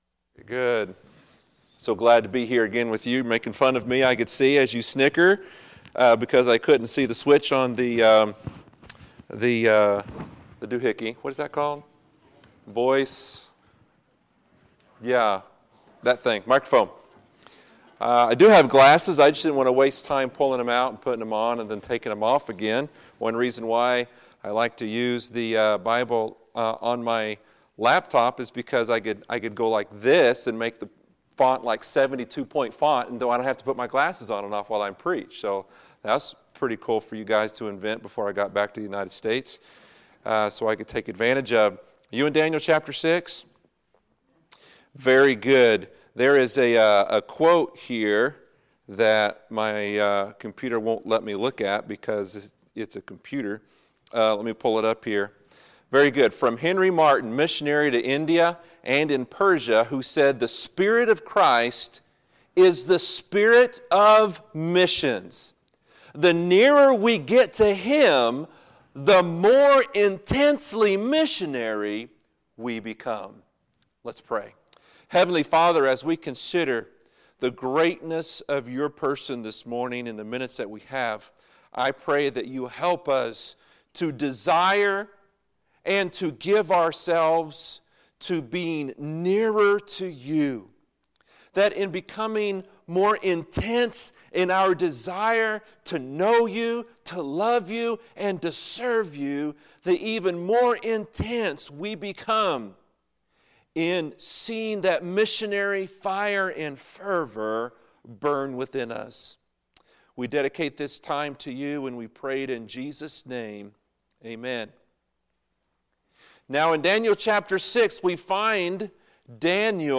Sunday, July 15, 2018 – Sunday Morning Service